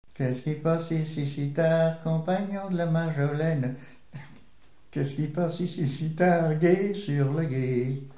Mémoires et Patrimoines vivants - RaddO est une base de données d'archives iconographiques et sonores.
Chevalier du guet - 007804 Thème : 0078 - L'enfance - Enfantines - rondes et jeux Résumé : Qu'est-ce qui passe ici si tard ?
Pièce musicale inédite